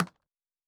Click (21).wav